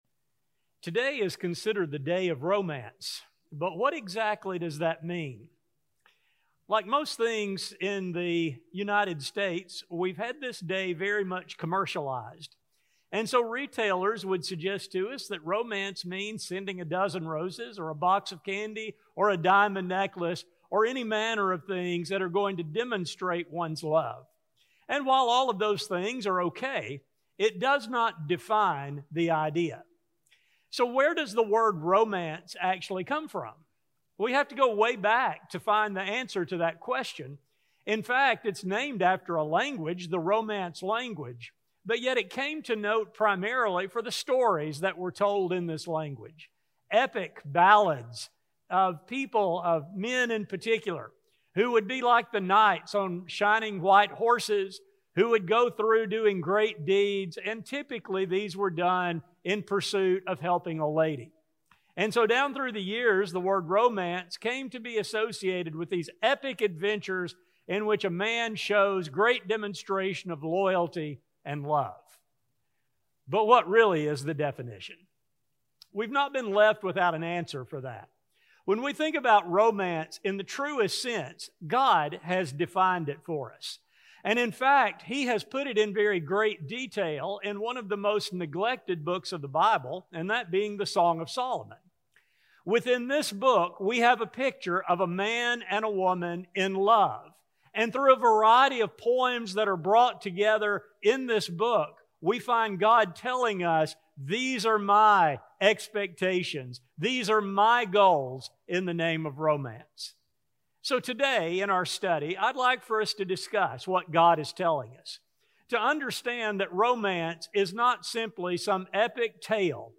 A sermon recording